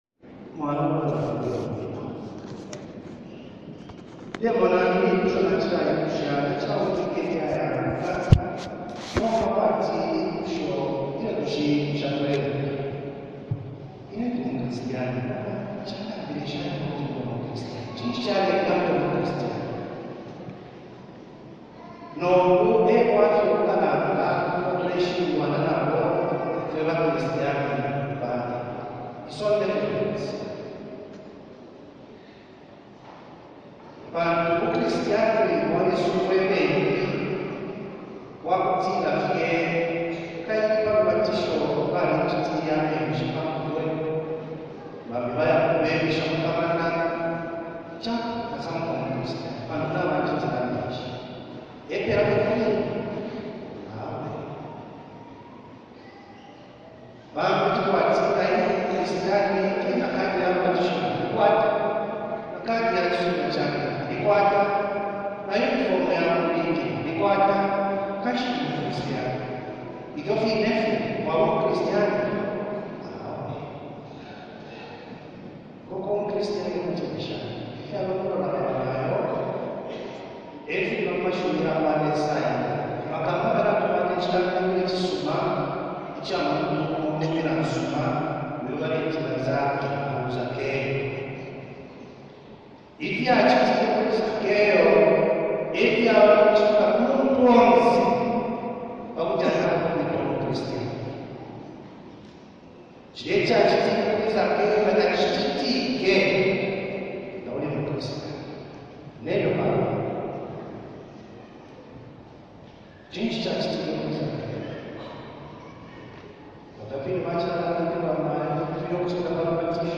Pastoral visit at Uganda Martyrs Parish ,luanshya – Catholic Diocese of Ndola
Mass began with the procession.